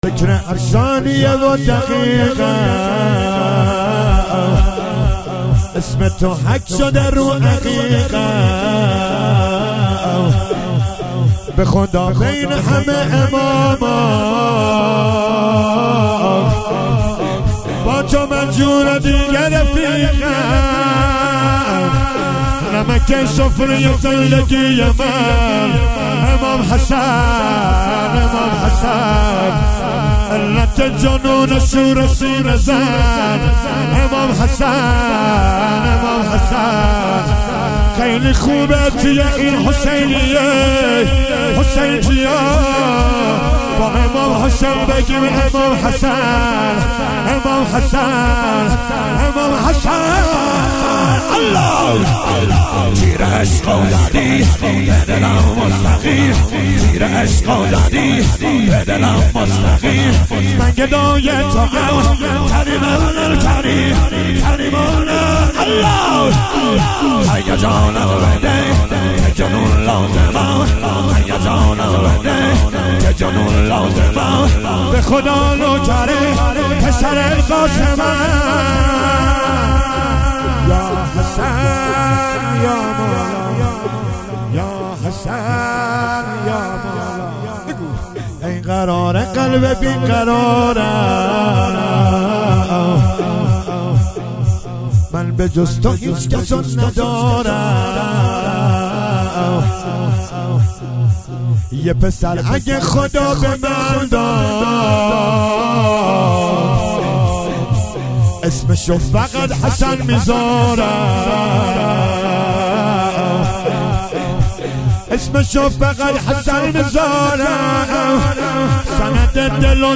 مداحی
محرم 1399 هیئت محبان الرقیه(س) شهر ری